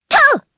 Hanus-Vox_Attack3_jp.wav